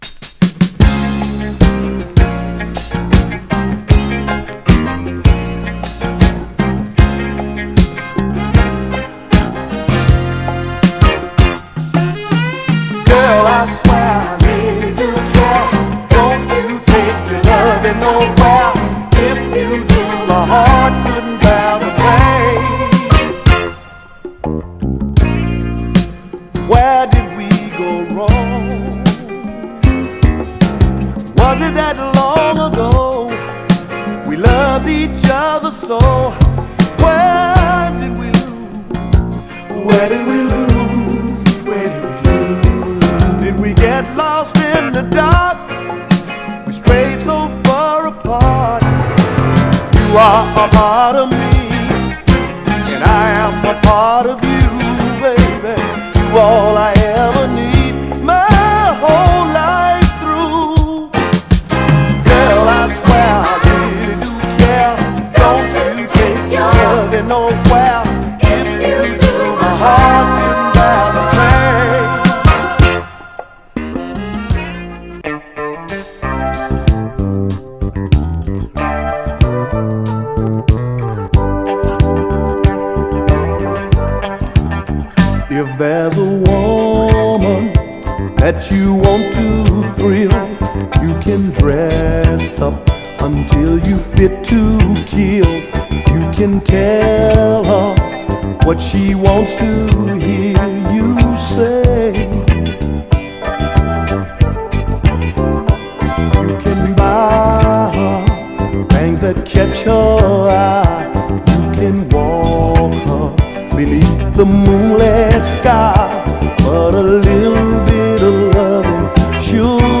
お手本のような隙のないメロウ・ステッパー
※試聴音源は実際にお送りする商品から録音したものです※